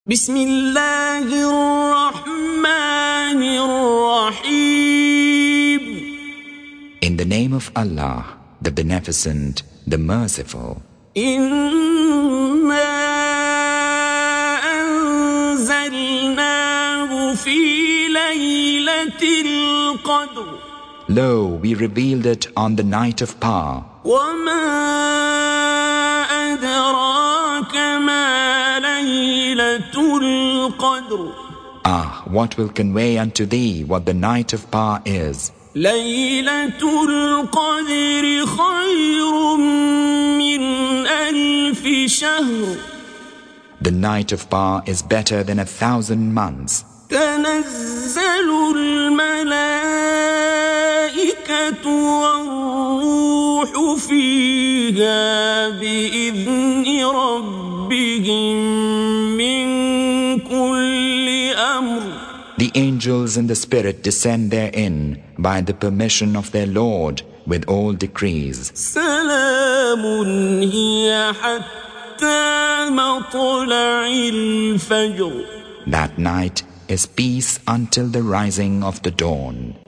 Surah Sequence تتابع السورة Download Surah حمّل السورة Reciting Mutarjamah Translation Audio for 97. Surah Al-Qadr سورة القدر N.B *Surah Includes Al-Basmalah Reciters Sequents تتابع التلاوات Reciters Repeats تكرار التلاوات